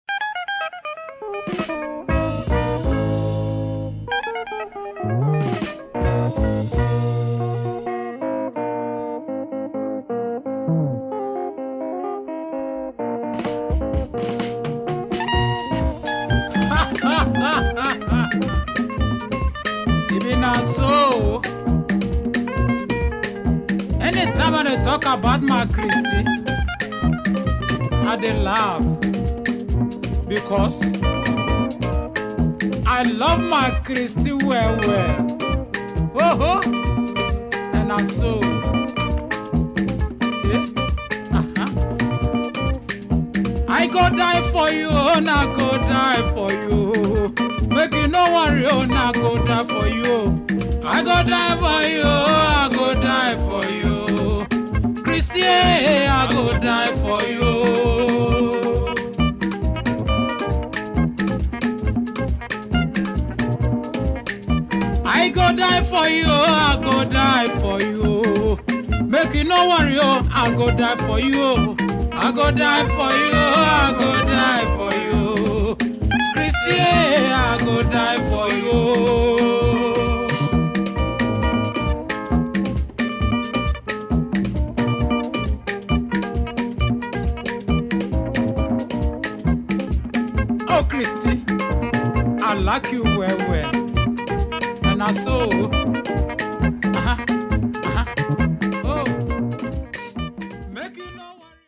Ghana highlife beauty !